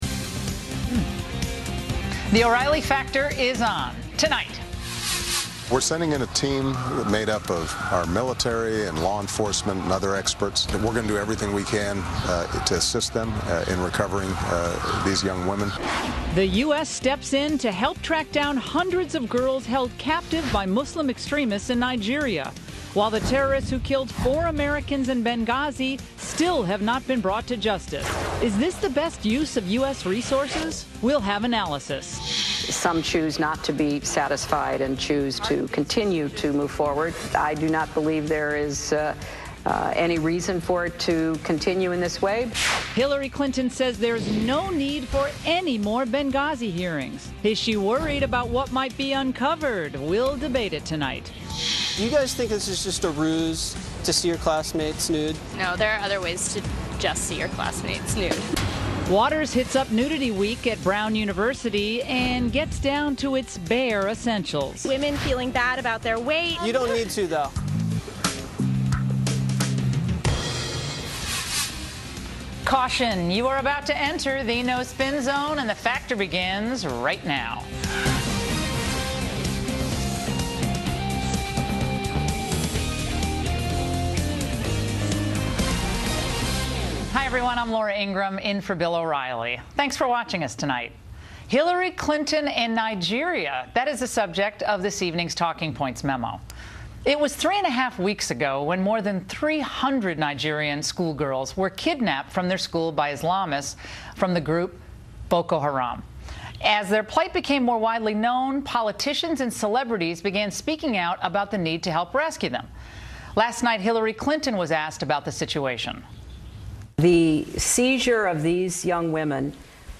Katherine Mangu-Ward appeared with guest host Laura Ingraham on Fox News Channel's The O'Reilly Factor to discuss hundreds of young girls being kidnapped in Nigeria, U.S. foreign policy, terrorism, and former Secretary of State Hillary Clinton.